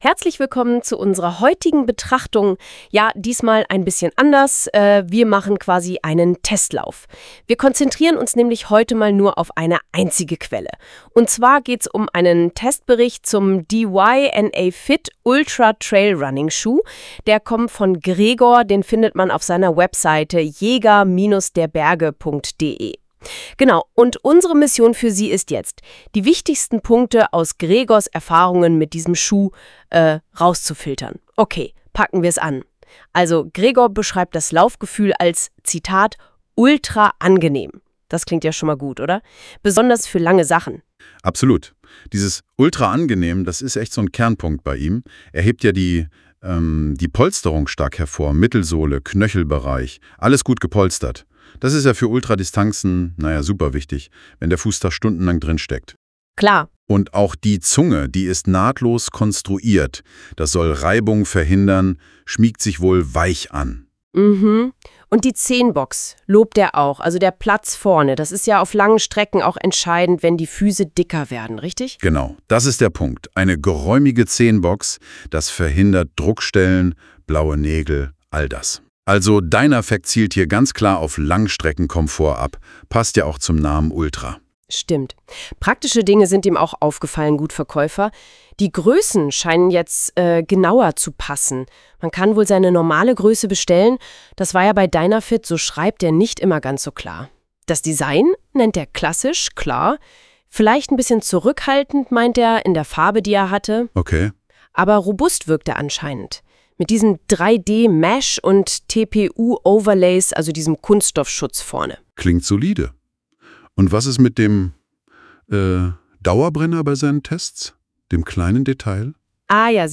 *AI Zusammenfassung